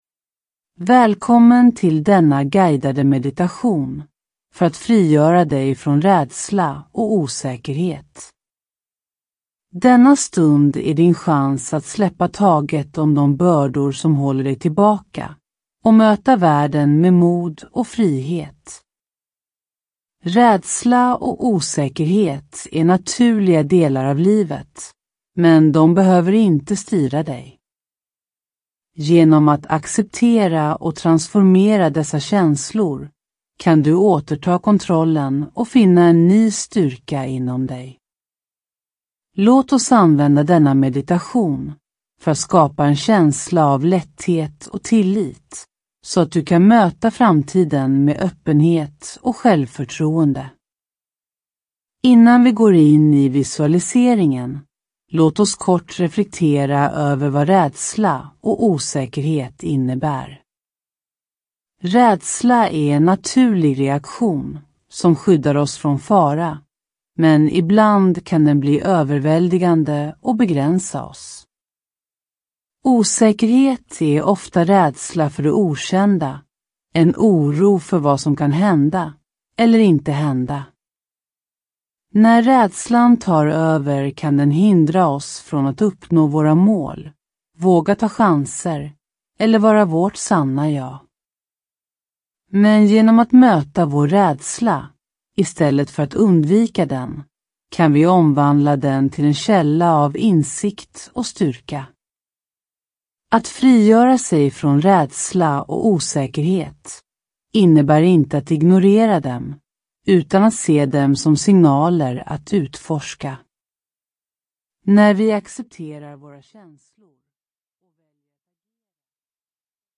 Ljudbok
Denna guidade meditation hjälper dig att möta dina rädslor med förståelse och kärlek, så att du kan släppa taget om de begränsningar som håller dig tillbaka.